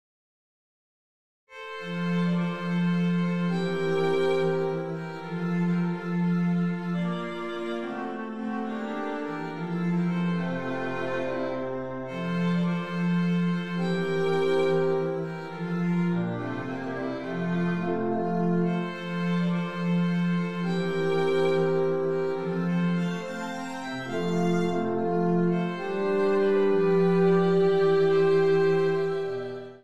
Kammerorchester-Sound